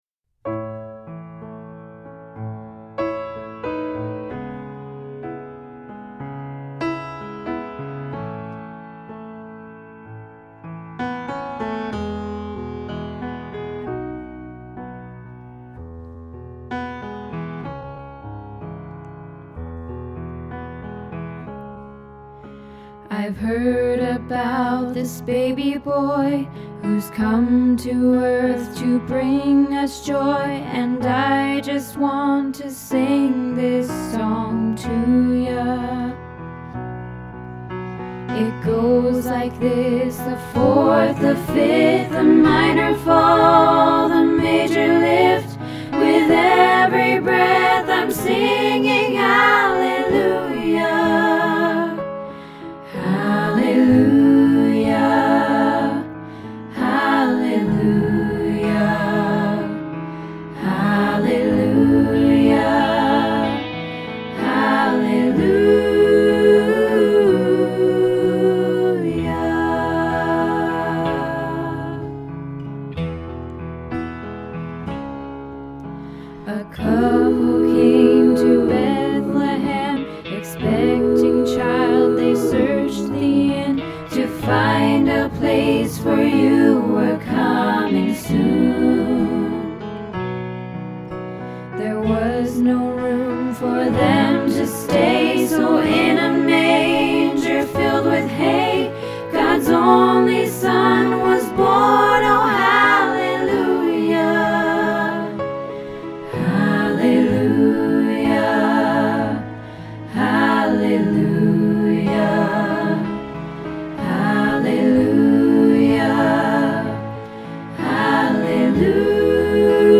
Hallelujah Christmas - Practice